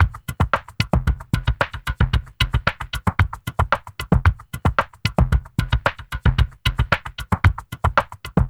LQT LOFI M-R.wav